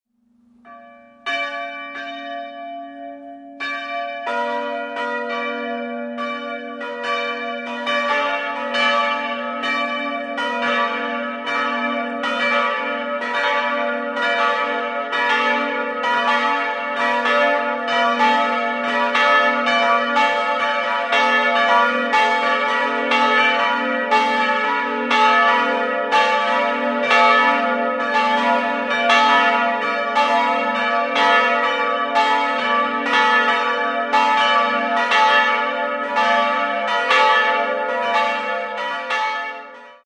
3-stimmiges Gloria-Geläute: a'-h'-d''
a' 500 kg 96 cm 1993
Matthäusglocke h' 350 kg 85 cm 1993
d'' 200 kg 71 cm 1993
Aufgrund der sehr tief liegenden Glockenstube (geschätzte Höhe: nur 8 - 10 m über dem Boden) und der großen Schallöffnungen klingen die Glocken in Kirchennähe extrem laut.